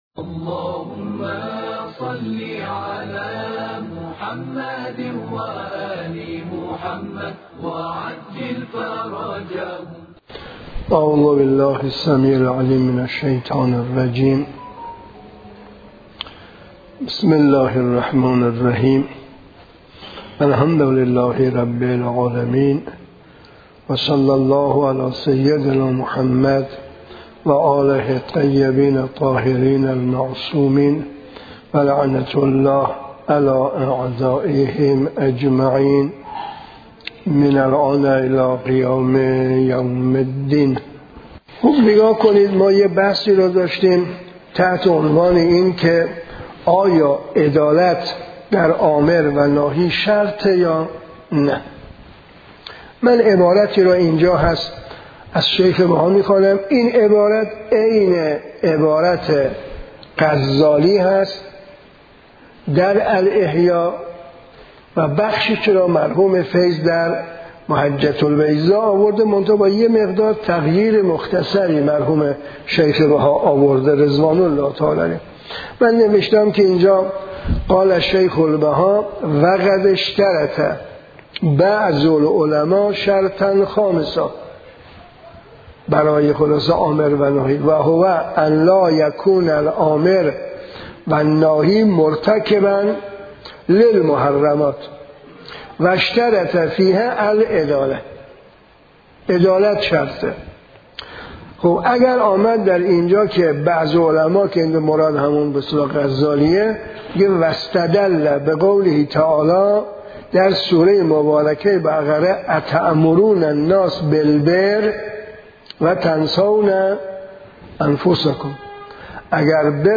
فقه حکمرانی - دروس خارج فقه معاصر